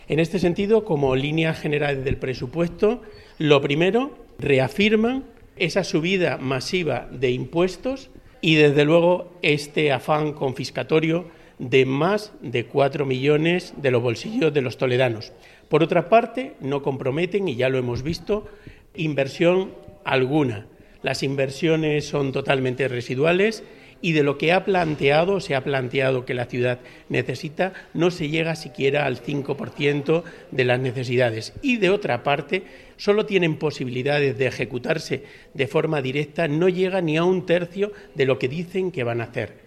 Así lo ha explicado el concejal socialista, Teo García, tras la Comisión de Hacienda celebrada este viernes y convocada ayer por la tarde y en la que el gobierno de Carlos Velázquez apenas ha dado información, simplemente se ha limitado en dar a conocer el calendario que seguirá la aprobación de estas cuentas.